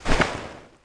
EnemyHit.wav